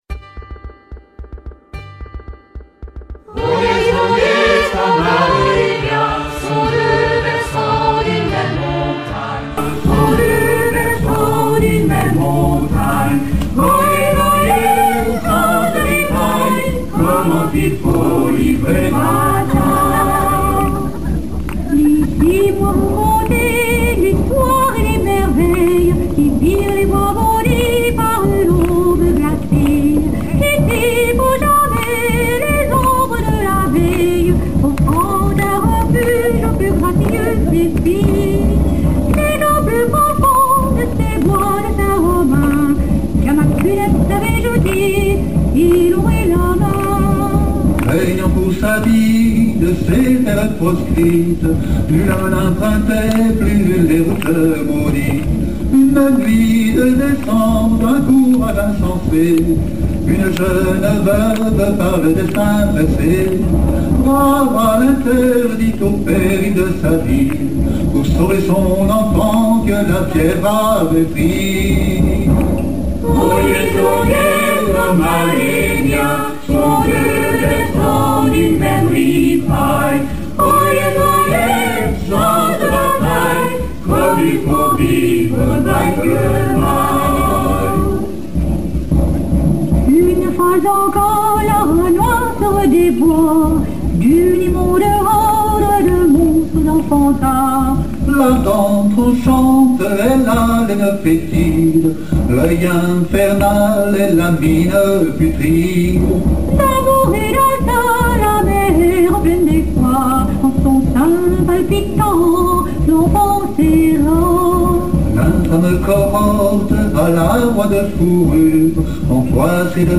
Vocal [89%] Choral [11%]